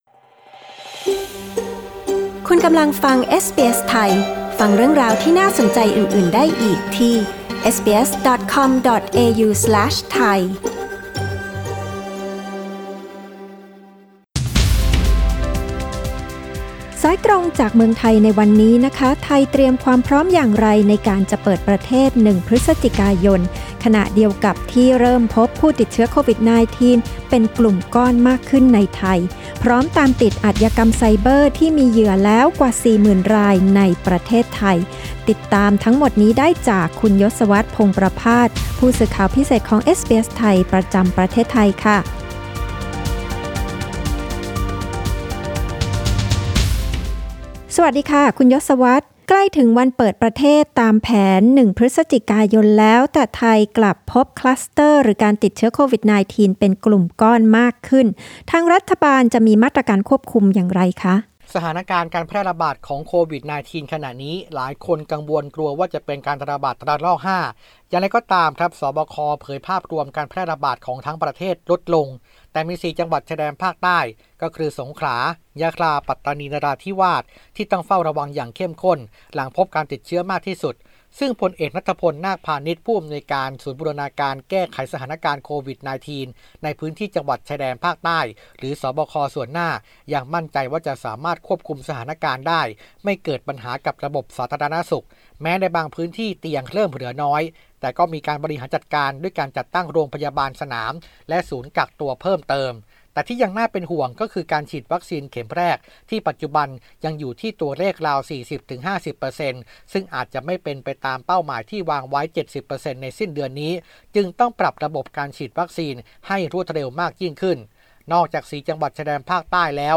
รายงานข่าวสายตรงจากเมืองไทย จากเอสบีเอส ไทย Source: Pixabay